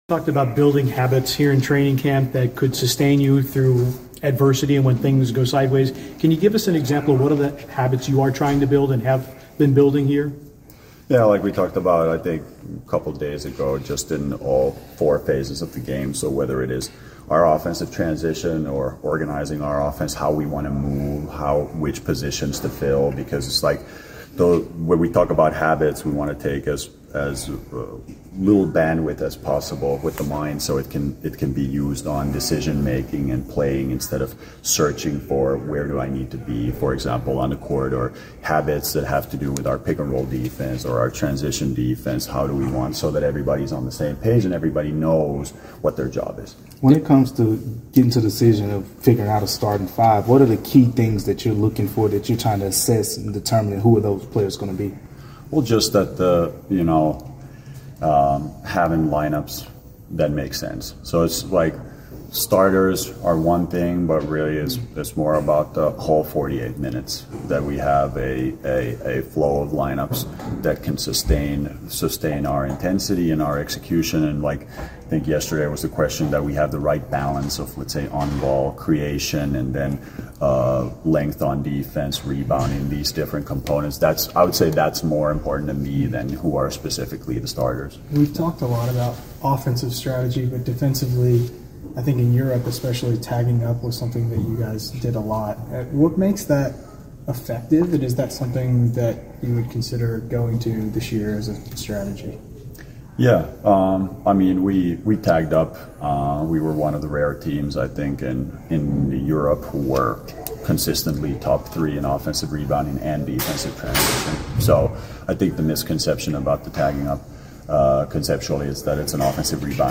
Memphis Grizzlies Coach Tuomas Iisalo Press Conference after the third day of Training Camp.